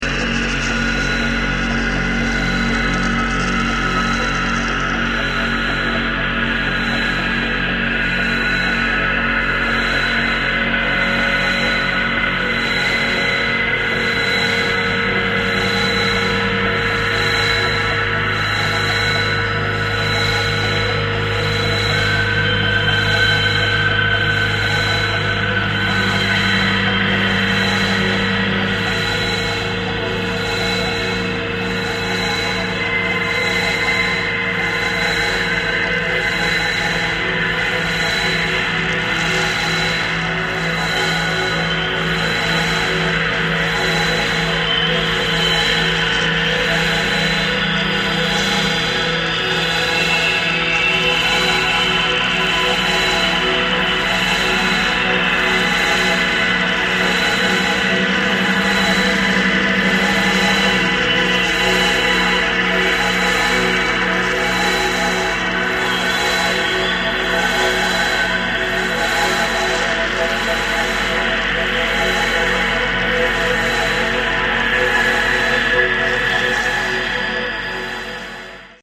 polyrhythmic percussive & textural guitar ambience